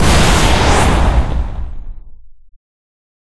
Fire8.ogg